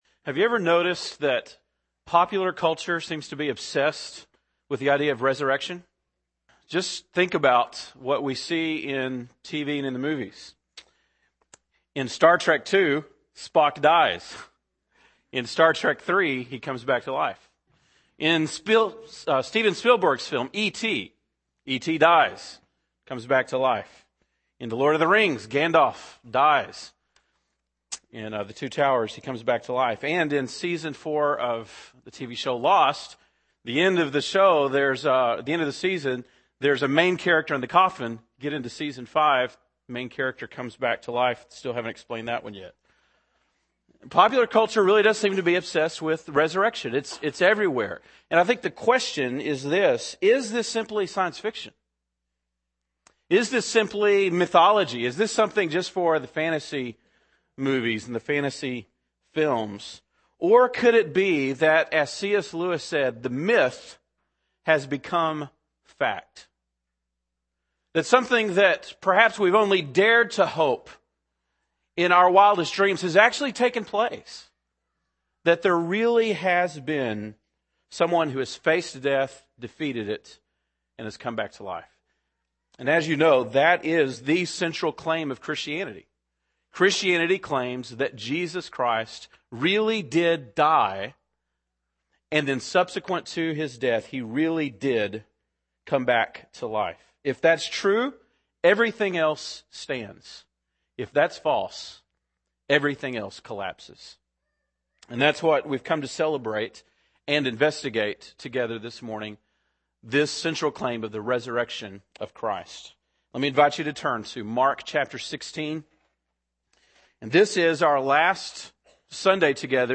April 12, 2009 (Sunday Morning)